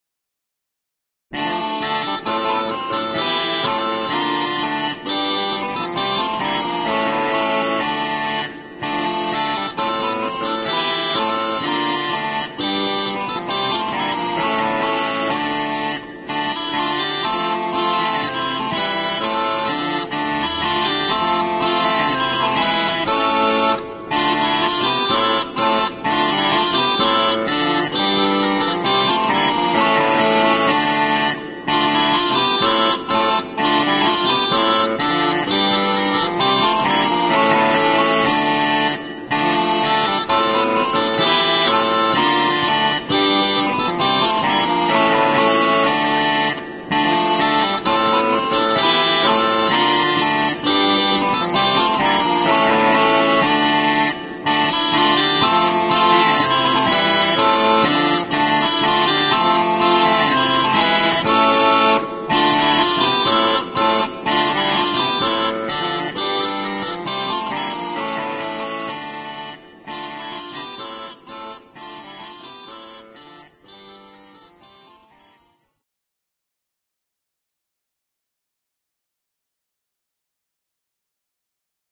Orgue
0901_Orgue_regale_Ronde_XVIe_Tylman_Susato_Orgue.mp3